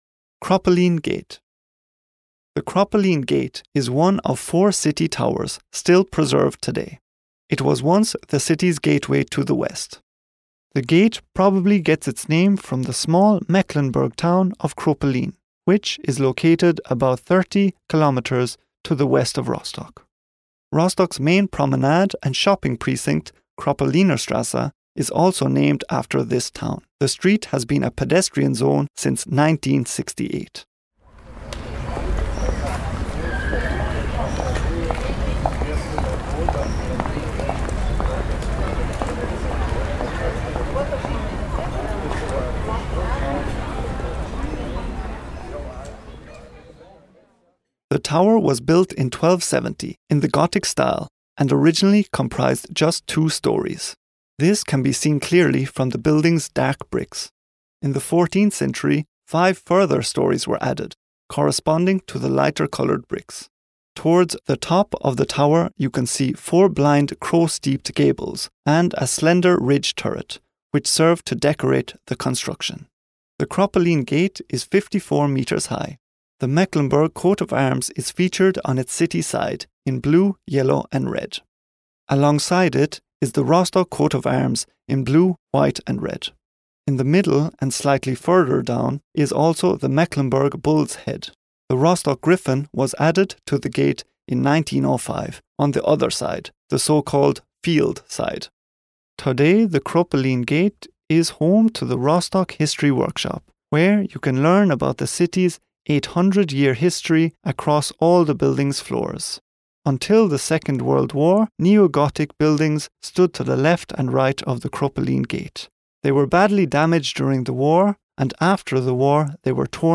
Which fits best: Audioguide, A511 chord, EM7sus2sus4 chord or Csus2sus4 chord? Audioguide